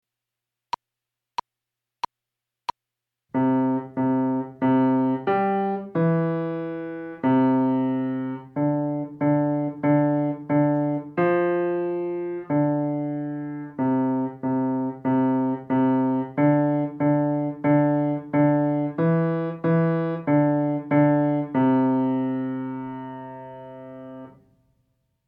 연습